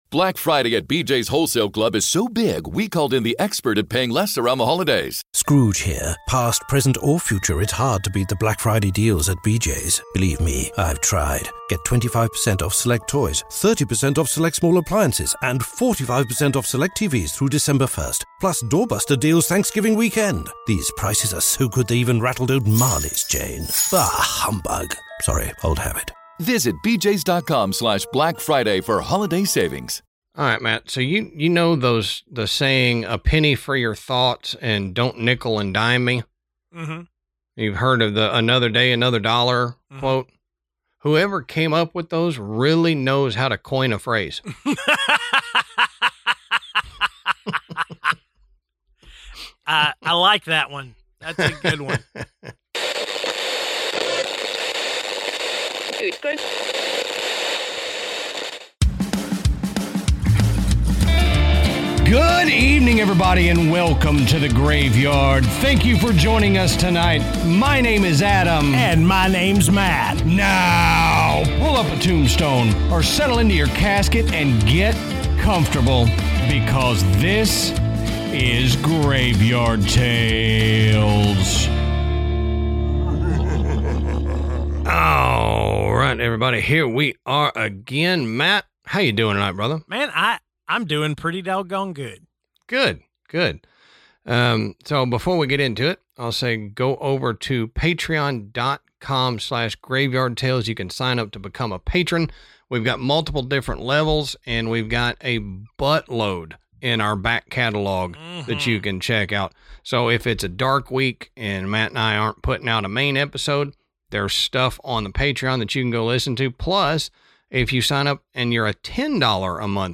We went live immediately after to recap our night and talk about the investigation and review our evidence.